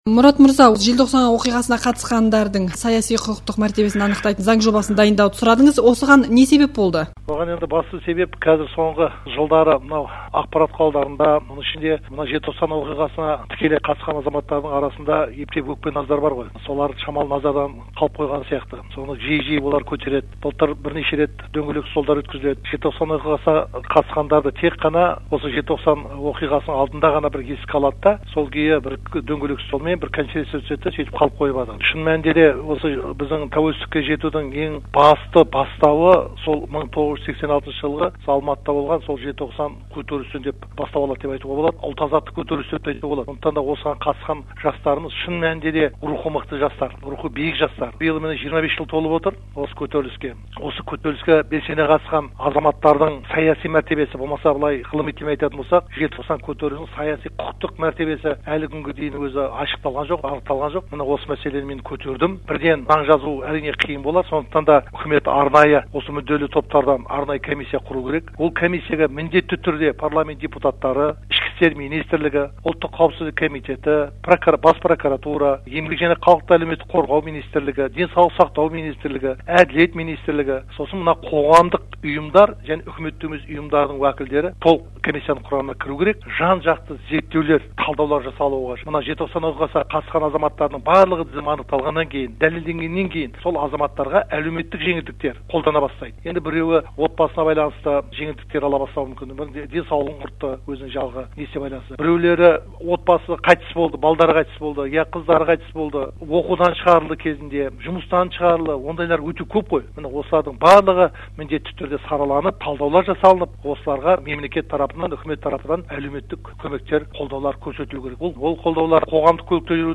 Сенат депутаты Мұрат Мұхаммедовпен сұқбат